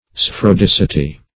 Search Result for " spheroidicity" : The Collaborative International Dictionary of English v.0.48: Spheroidicity \Sphe`roi*dic"i*ty\, Spheroidity \Sphe*roid"i*ty\, n. The quality or state of being spheroidal.
spheroidicity.mp3